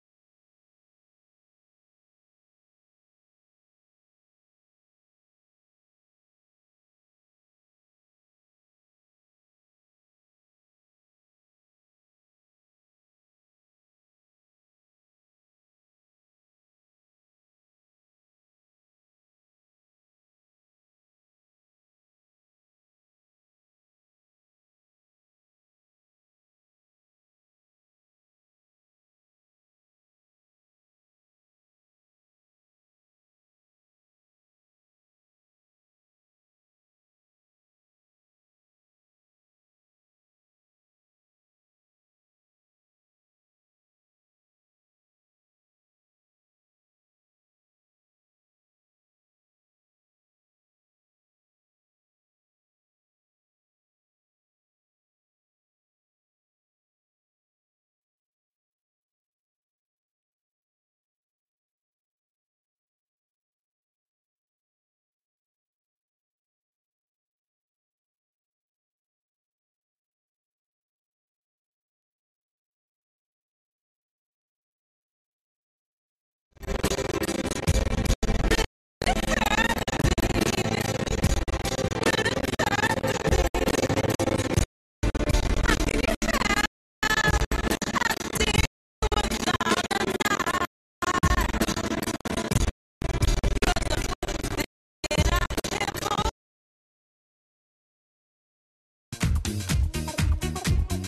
Replay of a live session